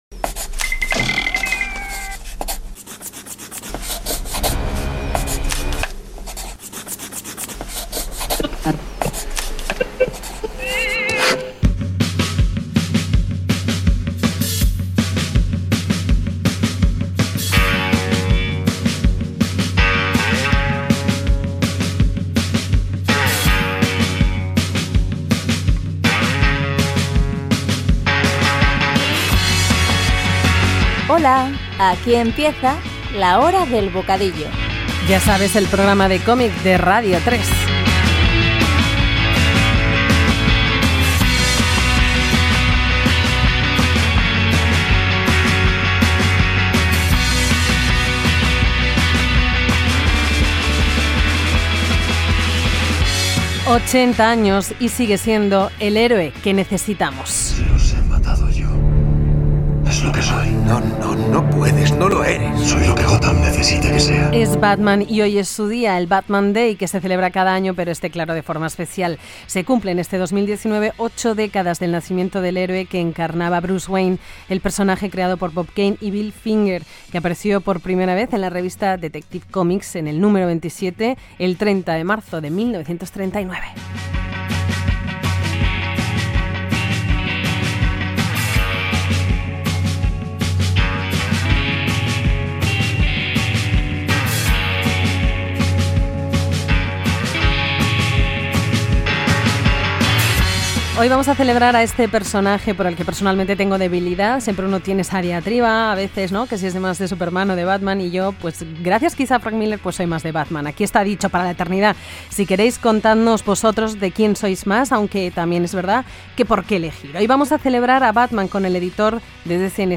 Sintonia i programa dedicat als 80 anys del personatge Batman amb una entrevista